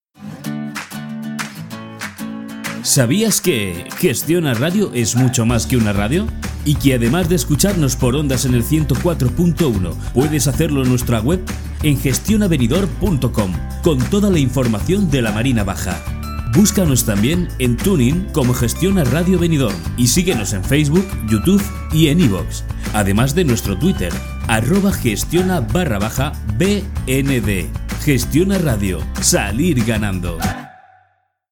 Sprechprobe: Industrie (Muttersprache):
Different records, imitations, voice actor Young Voice-Adult-Senior, Soft or Hard Commercial Voice, a little bit of everything.